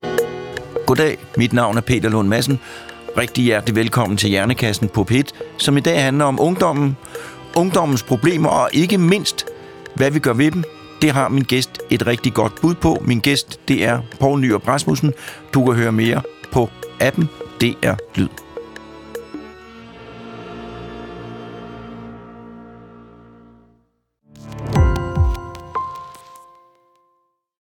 Peter Lund Madsen får hver uge besøg af eksperter i studiet. Alle emner kan blive belyst - lige fra menneskekroppens tarmflora til ekspeditioner på fremmede planeter.